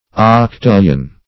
Octillion \Oc*til"lion\, n. [L. octo eight + -illion, as in E.